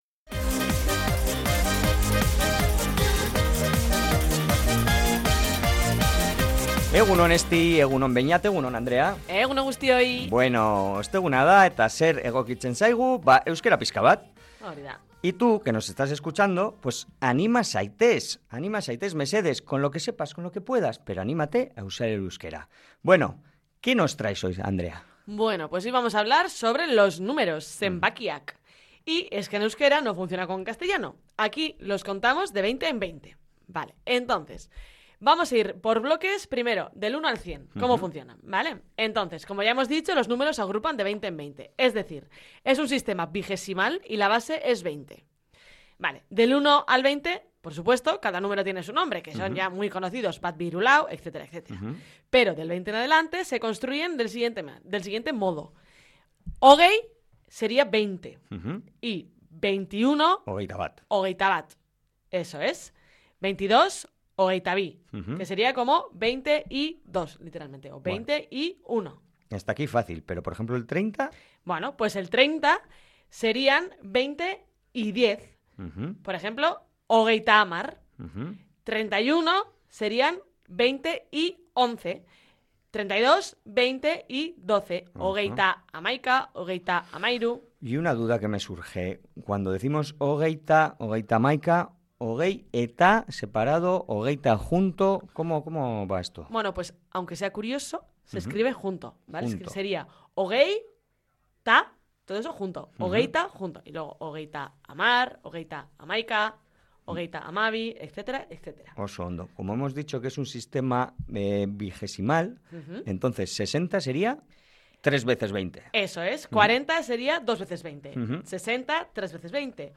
En la última entrega de la sección Piztu Euskara, dentro del programa EgunOn Bizkaia, los colaboradores han desgranado el funcionamiento de los zenbaki kardinalak (números cardinales).
En esos casos, el sonido se transforma, por lo que se pronuncia habitualmente como ‘ehundabat’ o ‘berreundahogei’, un matiz de fluidez que marca la diferencia en el habla cotidiana.
La sesión concluyó con ritmo musical, subrayando que el aprendizaje del euskera también entra por los oídos y que, con estas claves, cualquiera puede dominar las cifras.